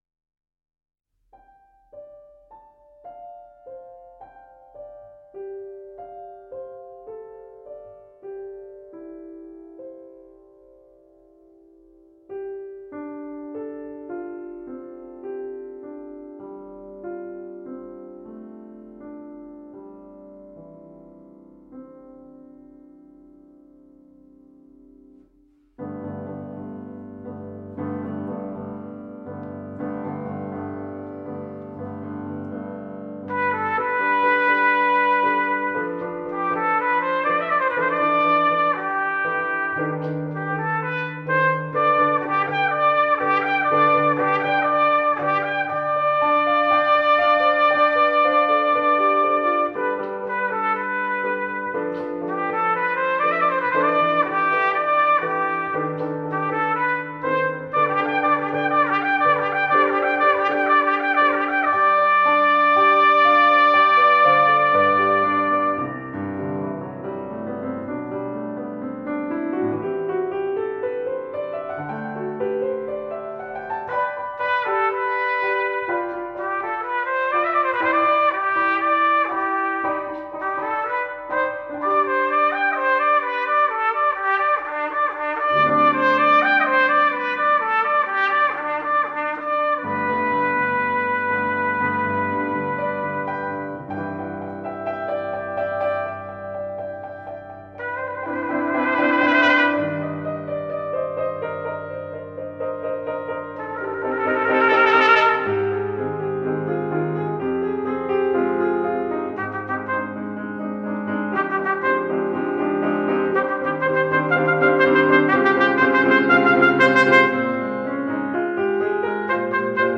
for Trumpet and String Orchestra (2008)
A solo violin remains at the end - one lone star.